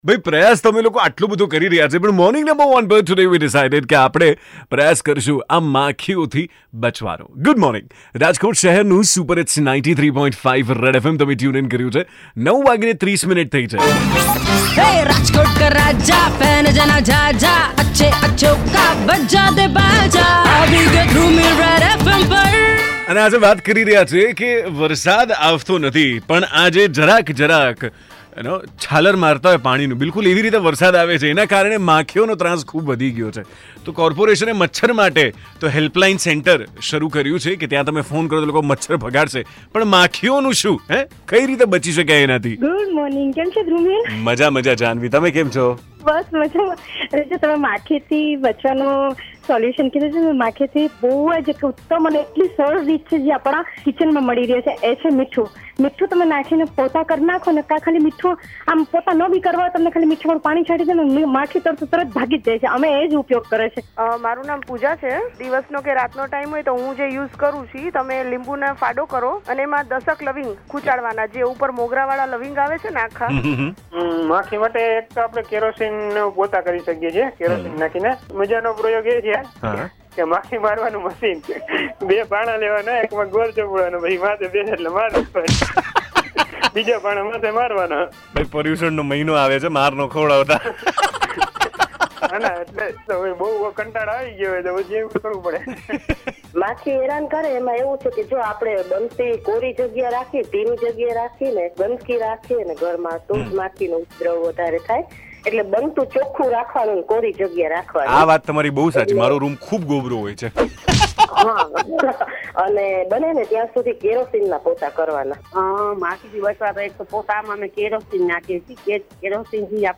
Listeners suggesting ways to get rid if flies